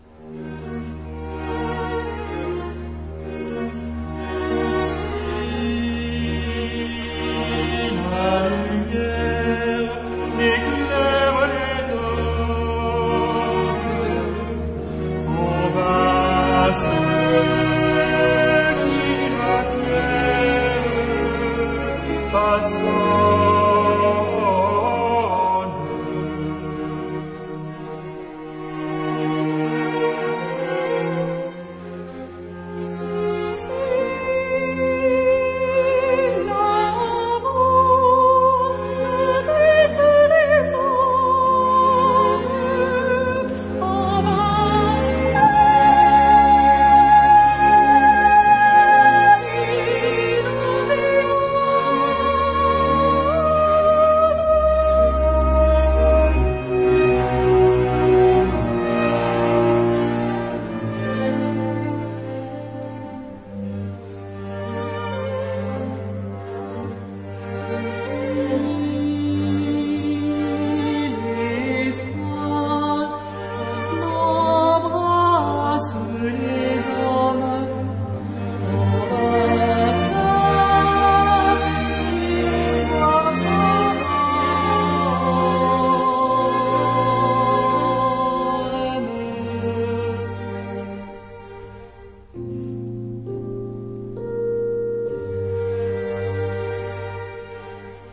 比較抱歉的是，試聽檔所剪輯的音樂，都偏向新古典風格，